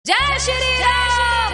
Devotional Ringtones